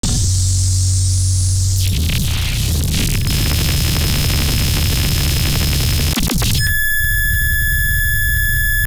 OTT Artifact 5.wav